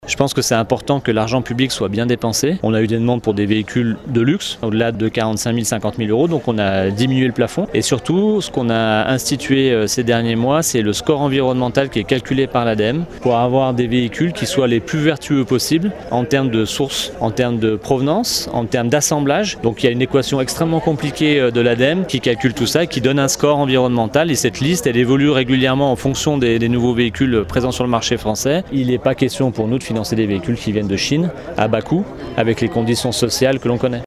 Plusieurs critères d’éligibilité ont été actés pour éviter les abus comme l’explique Raphaël Castera, le maire de Passy et Vice-président de la Communauté de communes Pays du Mont-Blanc, en charge de l’environnement